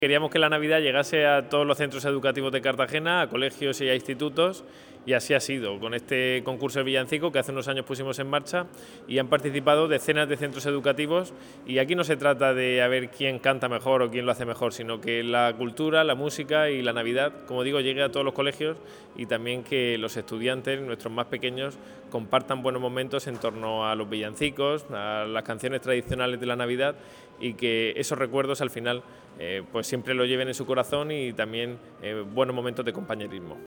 Enlace a Declaraciones del concejal de Educación, Ignacio Jáudenes